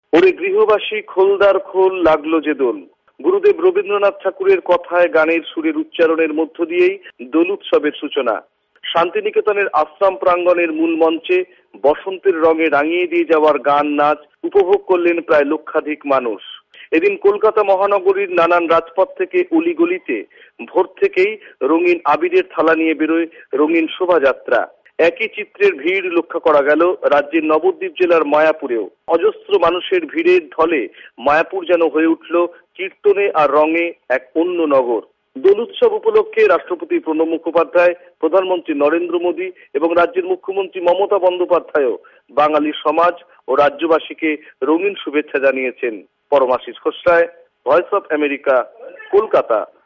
কলকাতা সংবাদদাতাদের রিপোর্ট